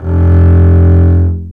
Index of /90_sSampleCDs/Roland L-CD702/VOL-1/STR_Cb Bowed/STR_Cb1 mf vb